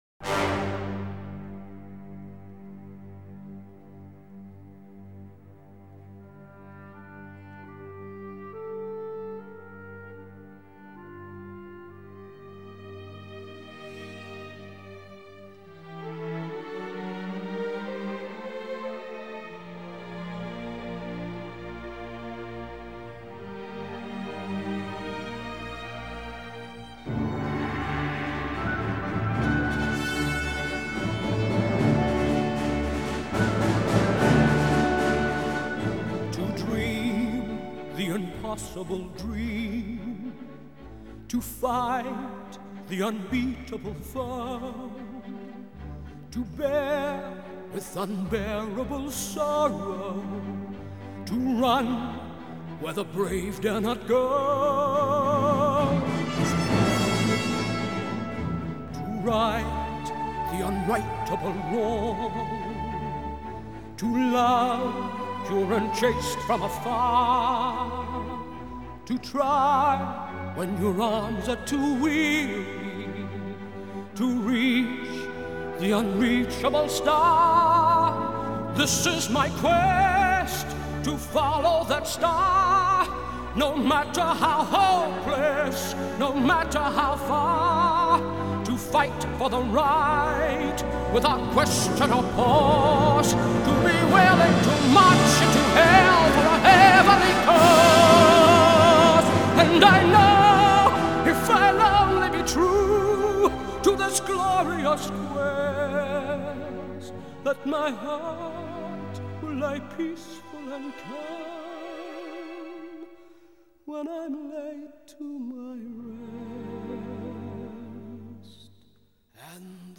Música contemporánea
Canto
Orquesta